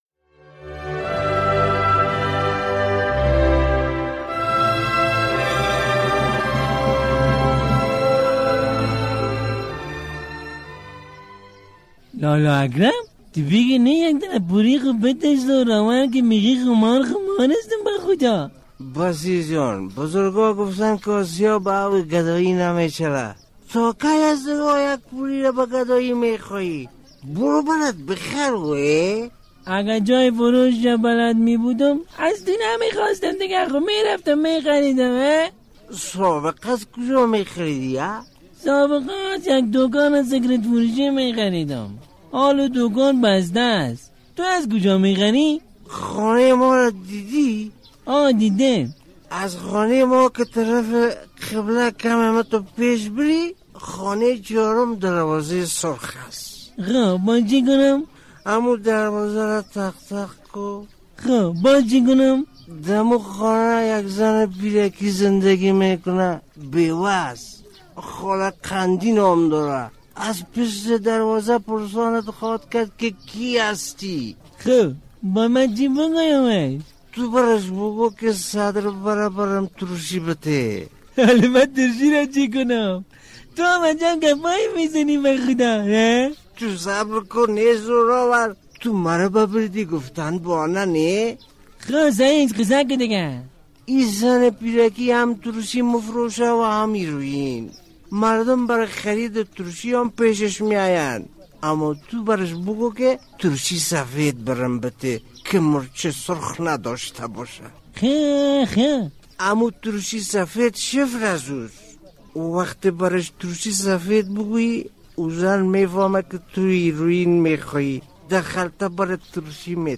درامه: چرا پولیس خانم درخانی را دستگیر کرده، و بعدأ چه اتفاق می‌افتد؟